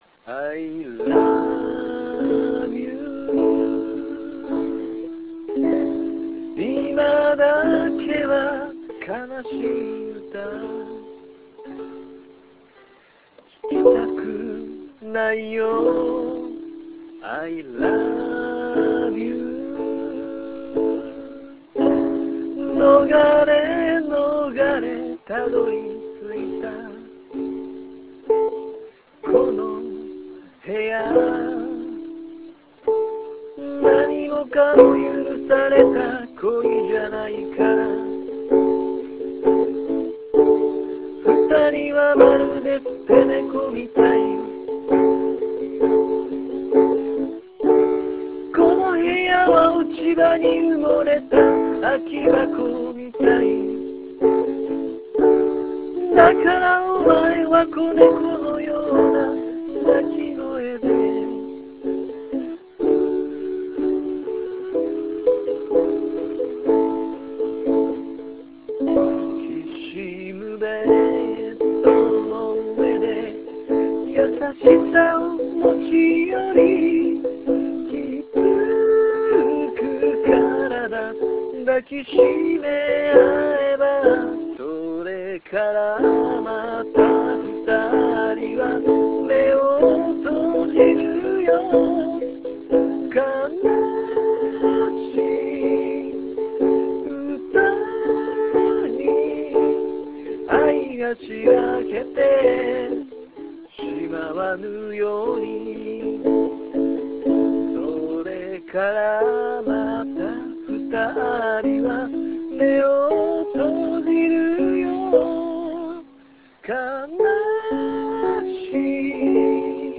Ukulele de song